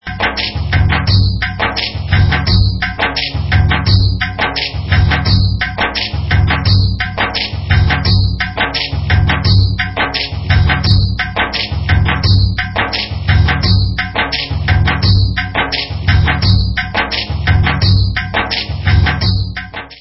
Rhythm Pattern 4